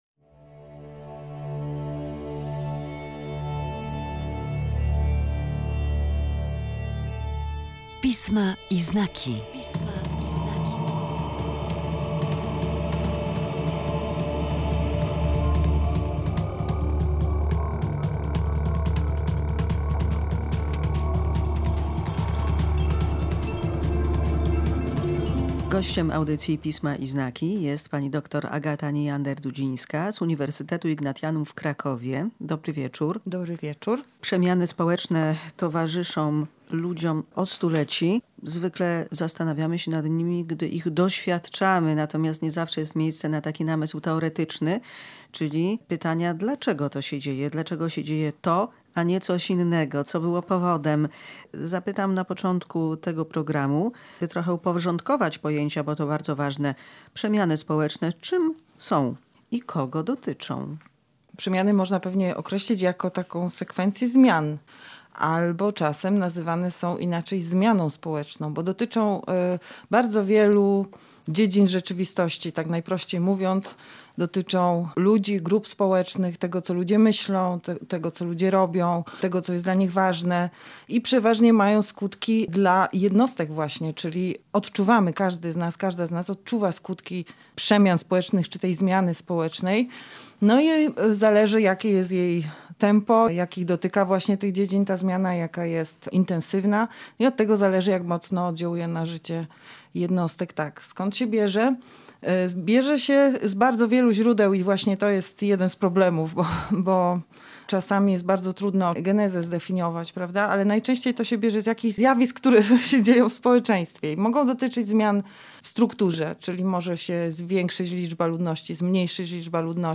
W audycji Pisma i znaki rozmawiamy o przyczynach, a także skutkach zmian społecznych.